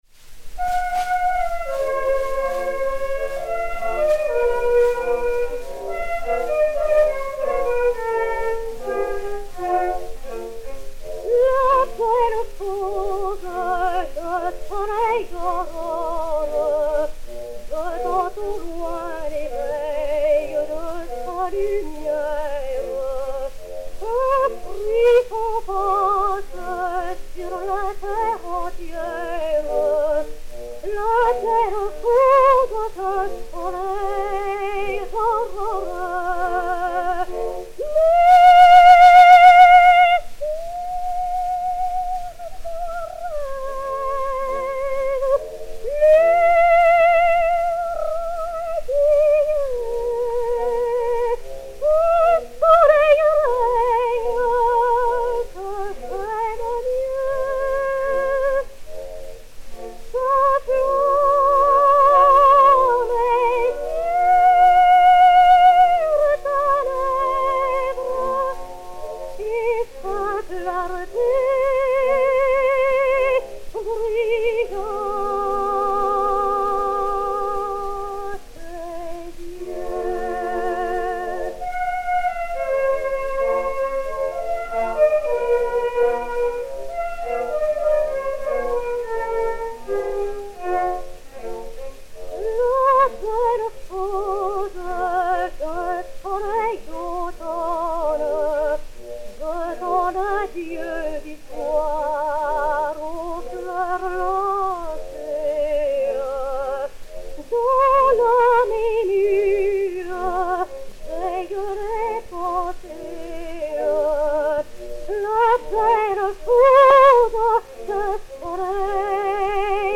enr. à Paris en 1905